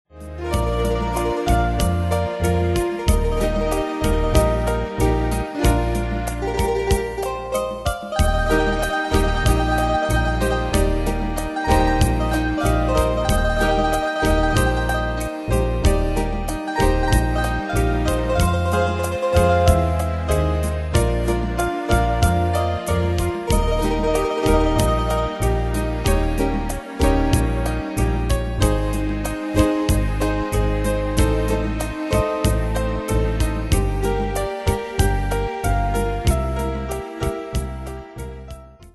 Style: PopFranco Ane/Year: 1997 Tempo: 94 Durée/Time: 2.57
Danse/Dance: Rhumba Cat Id.
Pro Backing Tracks